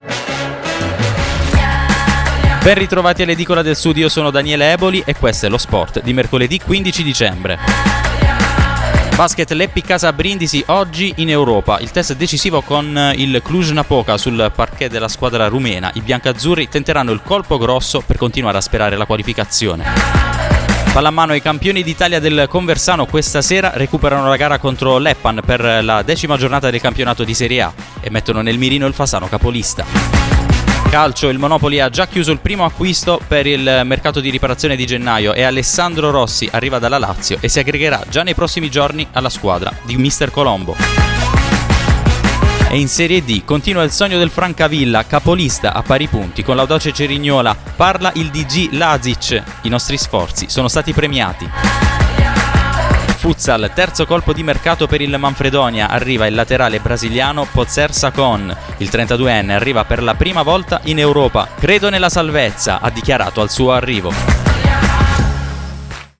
Giornale radio sportivo